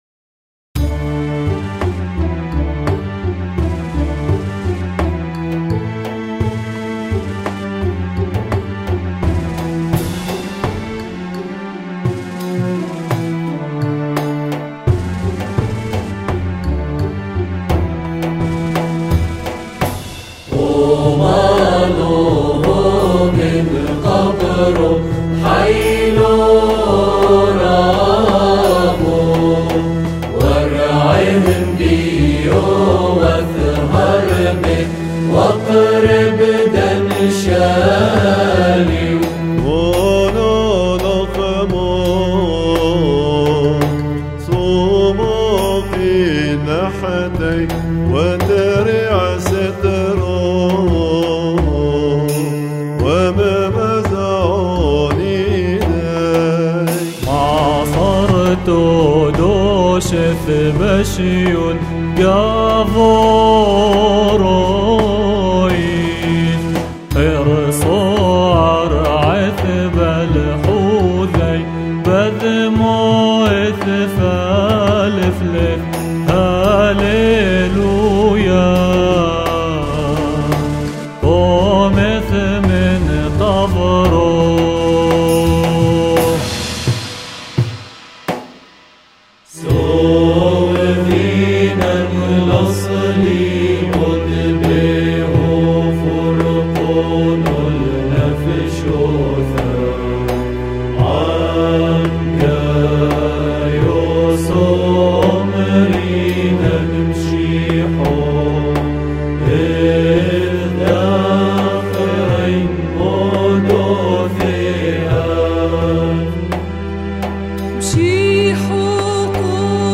التراث السرياني >>الموسيقى السريانيّة >> ترتيلة نُحثن هوَي
المرتلون:
تم التسجيل في ستوديو دير الشرفة - لبنان